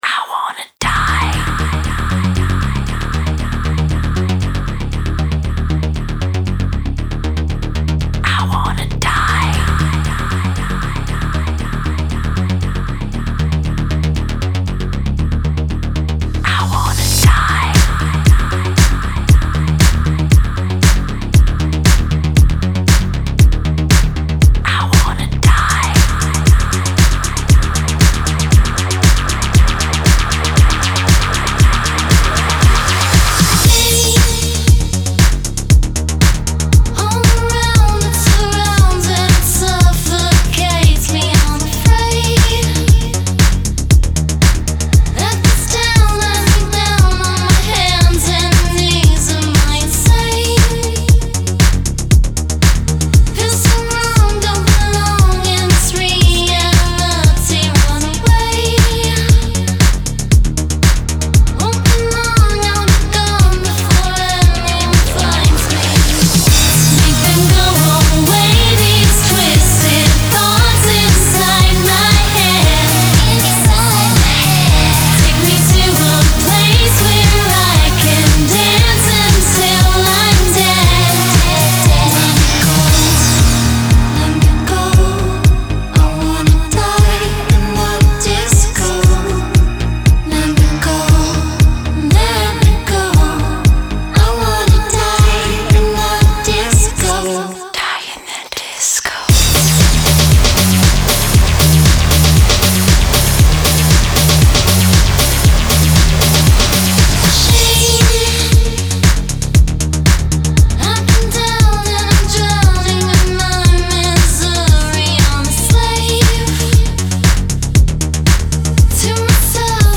BPM117-117
Audio QualityPerfect (High Quality)
Dark Pop song for StepMania, ITGmania, Project Outfox